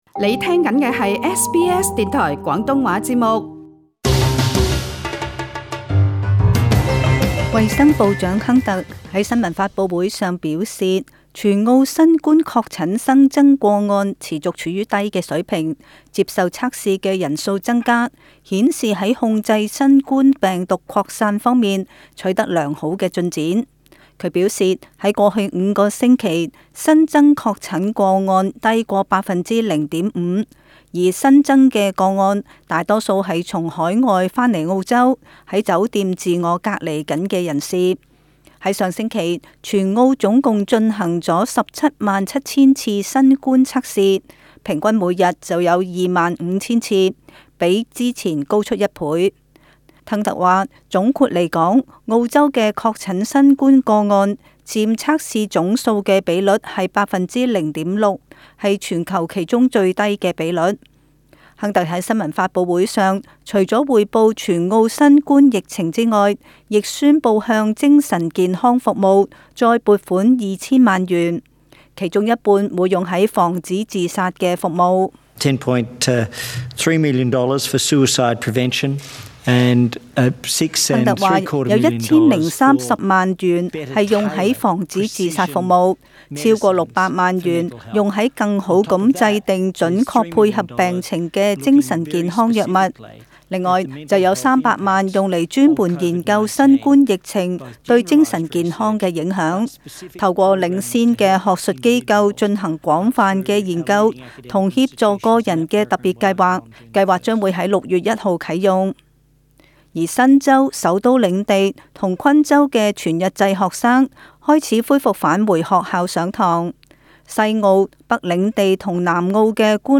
【時事報導】